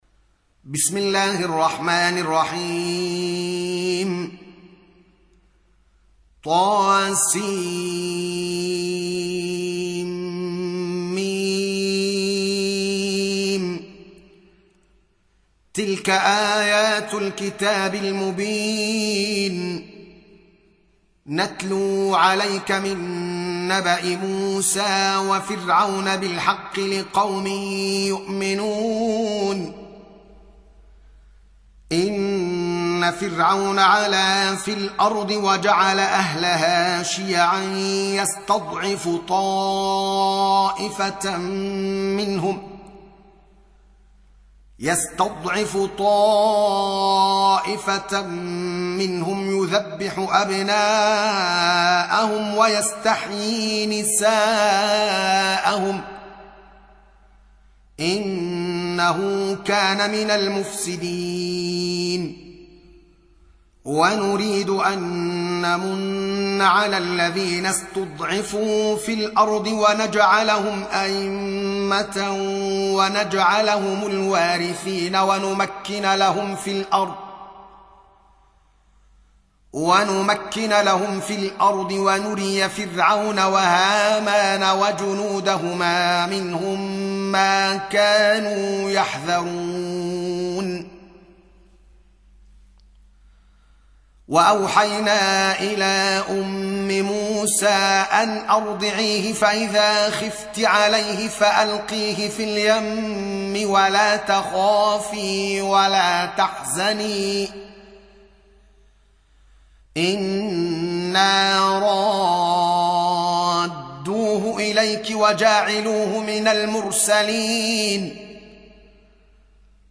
Surah Repeating تكرار السورة Download Surah حمّل السورة Reciting Murattalah Audio for 28. Surah Al-Qasas سورة القصص N.B *Surah Includes Al-Basmalah Reciters Sequents تتابع التلاوات Reciters Repeats تكرار التلاوات